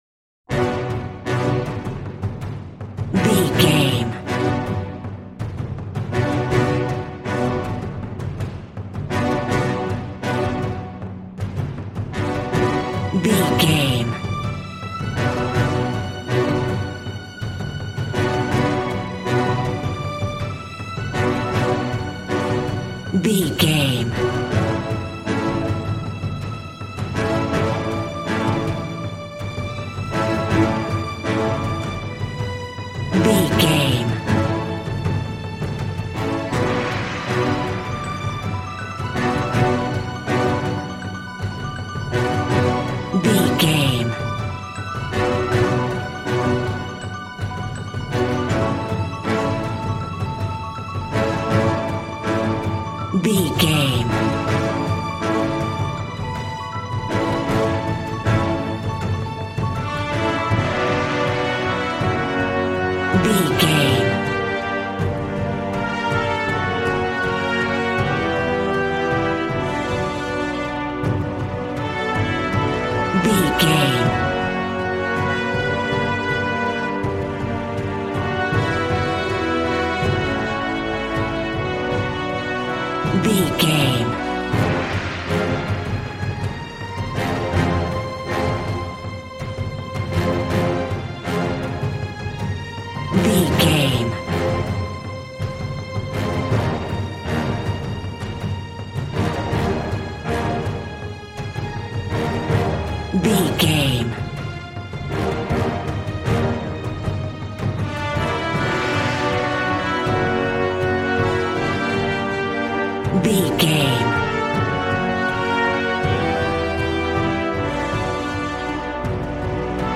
Action and Fantasy music for an epic dramatic world!
Aeolian/Minor
groovy
drums
bass guitar
electric guitar